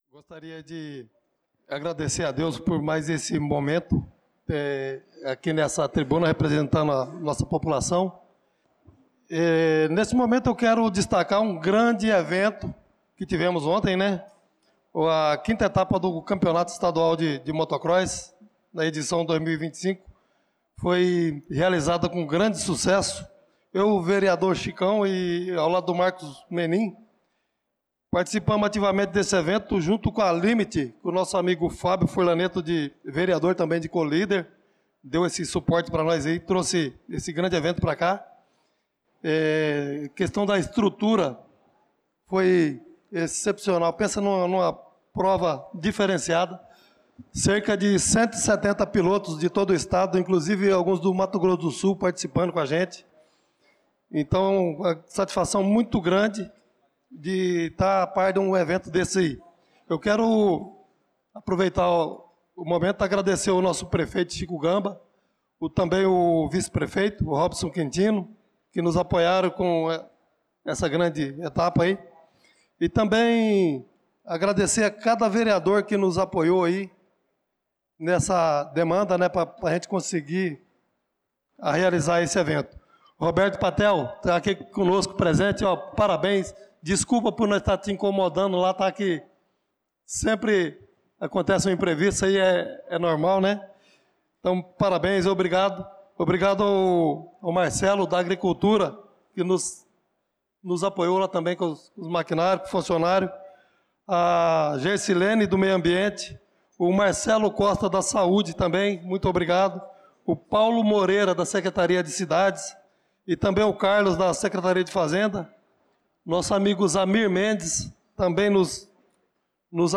Pronunciamento do vereador Chicão Motocross na Sessão Ordinária do dia 07/07/2025.